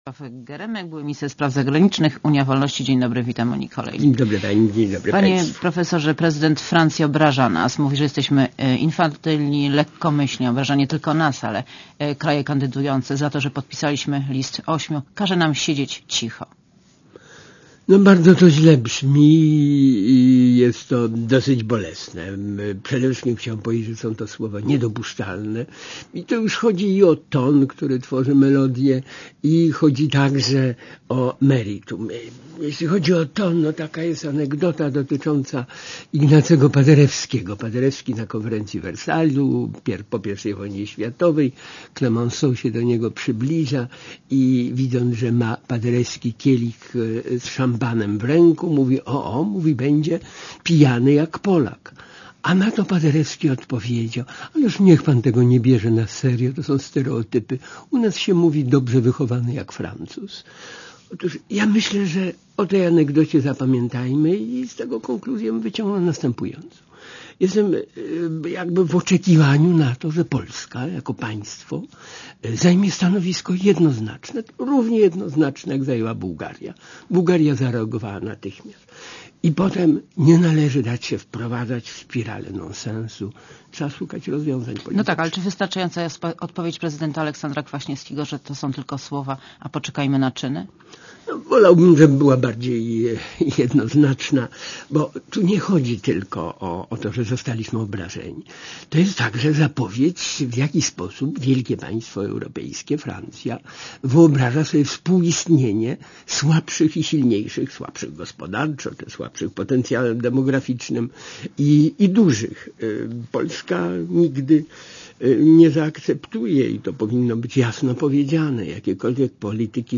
Monika Olejnik rozmawia z profesorem Bronisławem Geremkiem